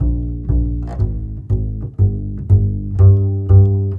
Rock-Pop 11 Bass 04.wav